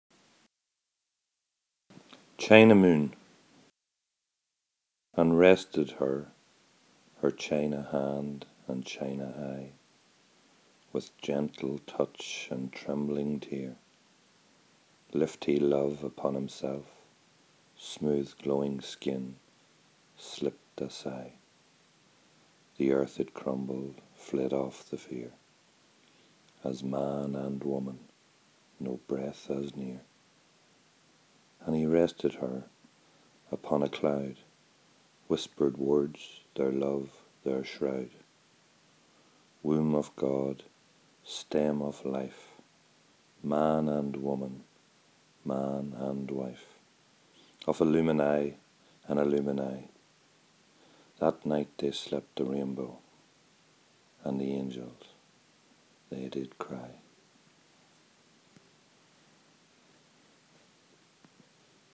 Poem read by the author